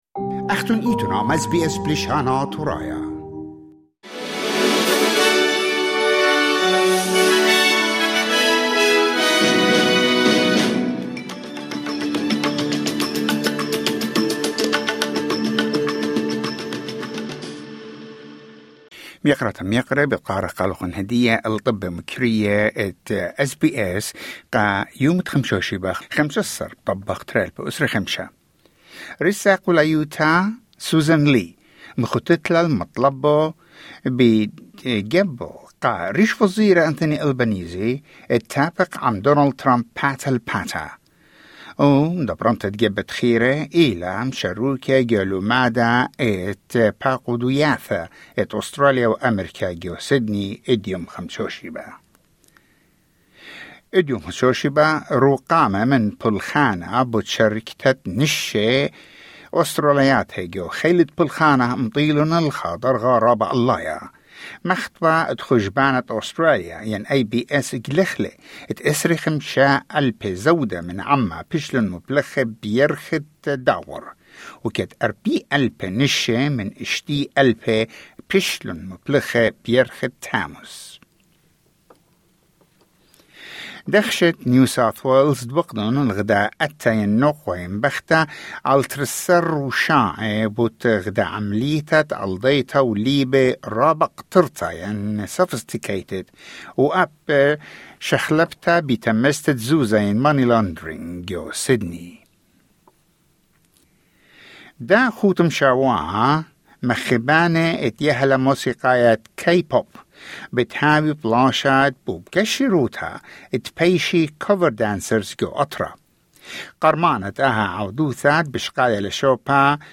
Newsflash: 14 August 2025